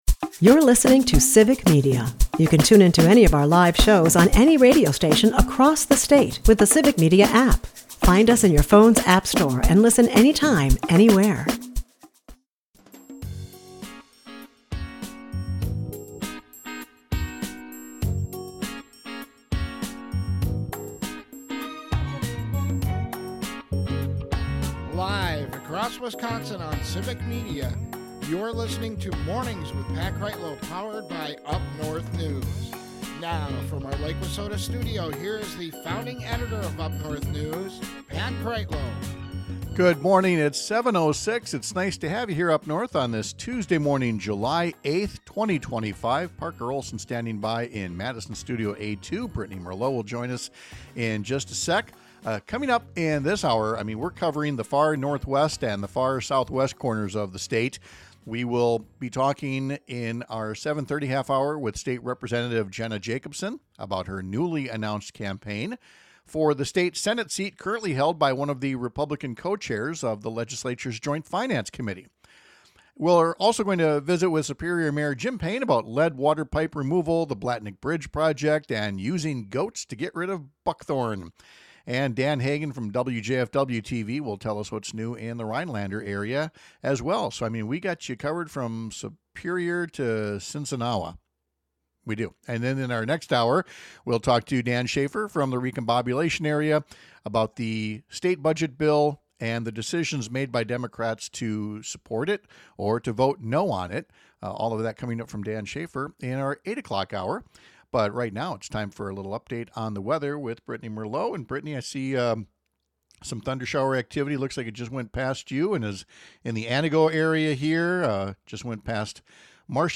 We cover the far northwest and southwest corners of Wisconsin this hour. We’ll talk to state Rep. Jenna Jacobson about her newly-announced campaign for the State Senate seat now held by the Republican co-chair of the Legislature’s Joint Finance Committee.